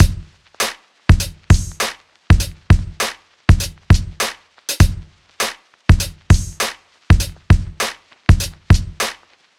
Unison Funk - 2 - 100bpm.wav